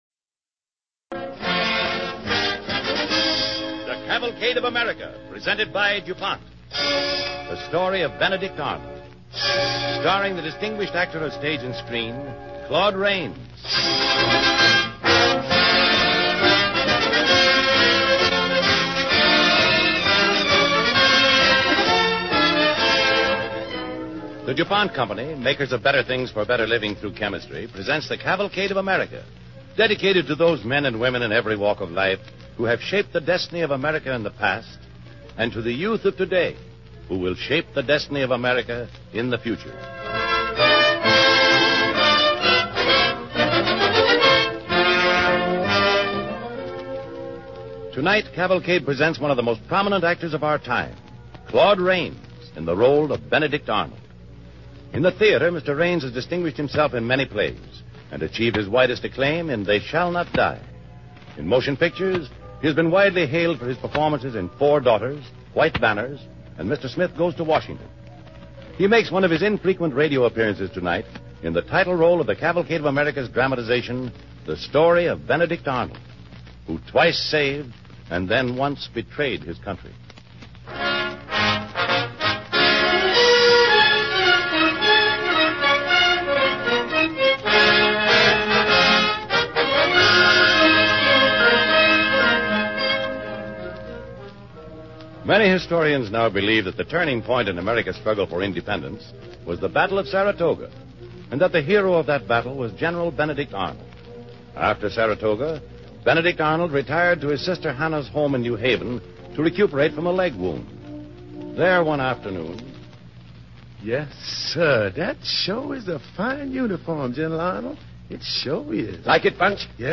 Listen to and download the Cavalcade of America Radio Program, The Story of Benedict Arnold, starring Claude Rains